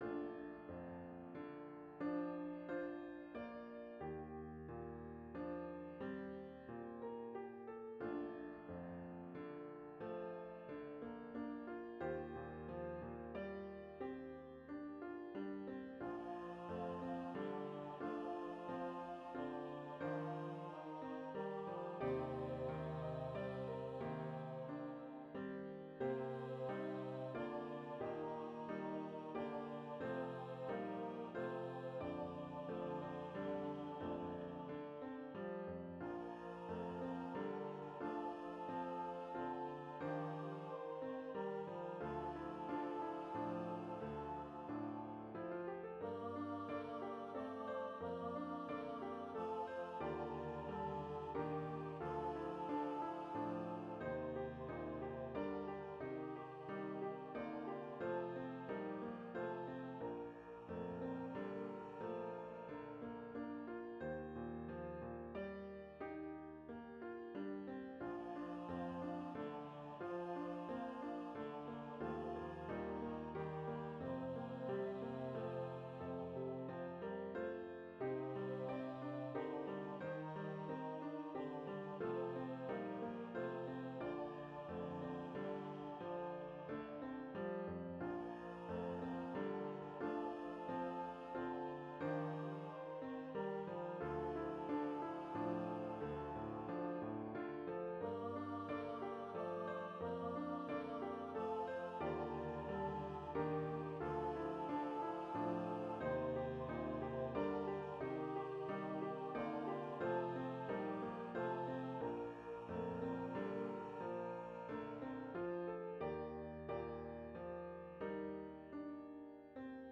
Voicing/Instrumentation: 2 part choir We also have other 40 arrangements of " Jesus, Once of Humble Birth ".